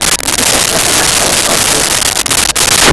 Crowd Laughing 02
Crowd Laughing 02.wav